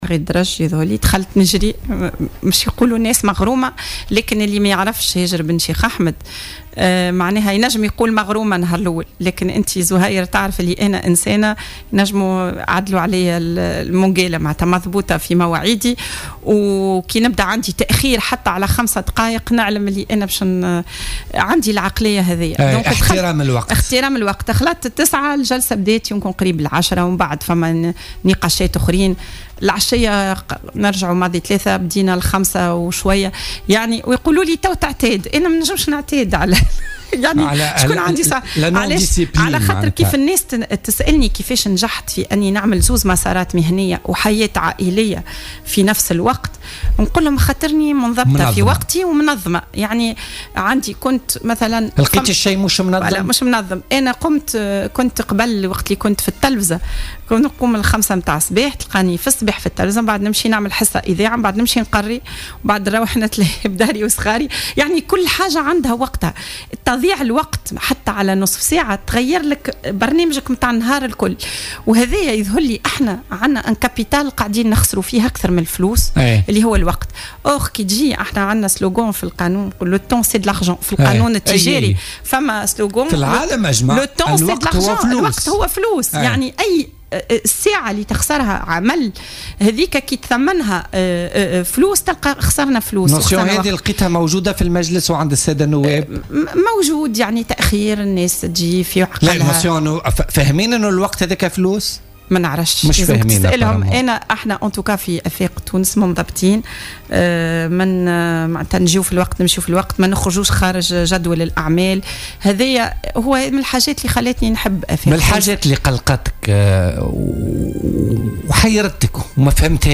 وأوضحت ضيفة "بوليتيكا" بـ "الجوهرة أف ام" أن هذا التهاون لاحظته لدى بعض النواب ويتعلق أساسا بعدم احترام الوقت والهندام بالإضافة إلى غياب ما وصفته بـ "الكاريزما"، بحسب تعبيرها، وتابعت: "لاحظت نقصا في "الكاريزما".